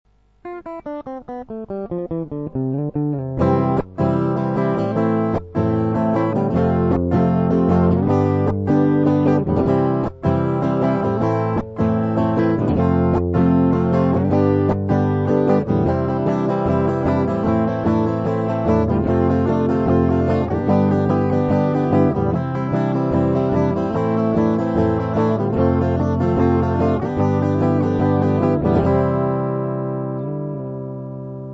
Проигрыш (C - Am - F - G):